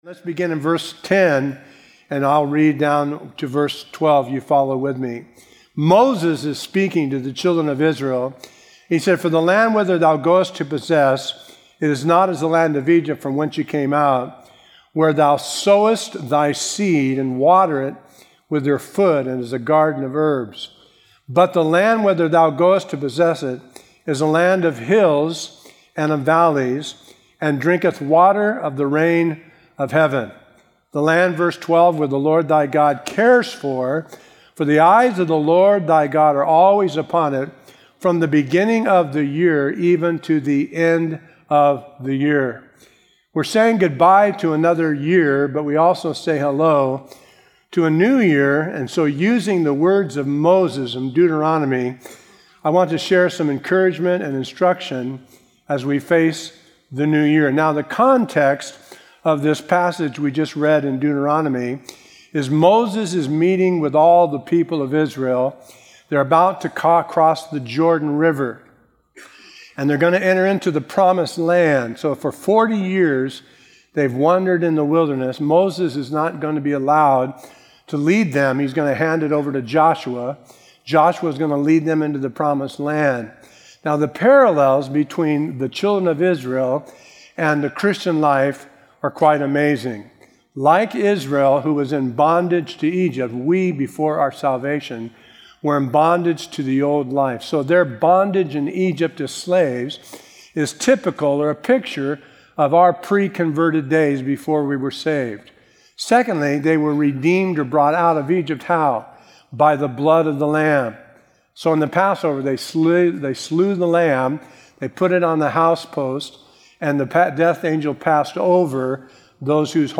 A verse-by-verse sermon through Deuteronomy 11:10-12